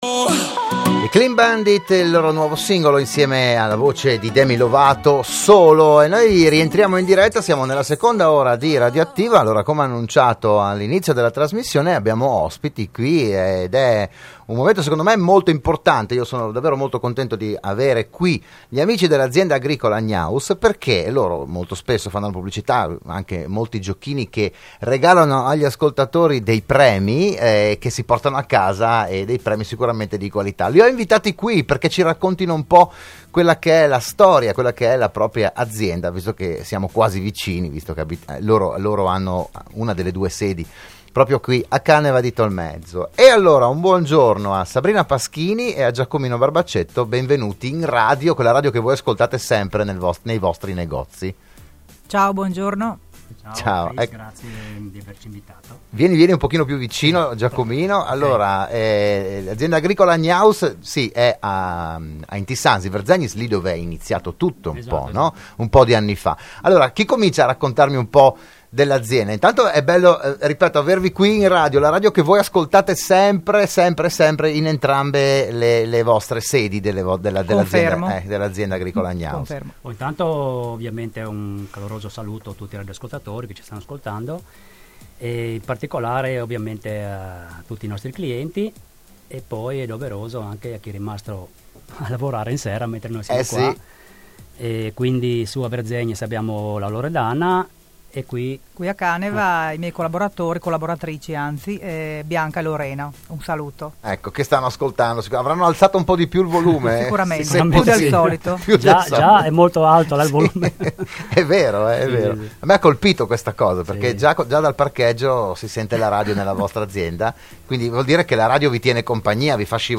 gnausintervista.mp3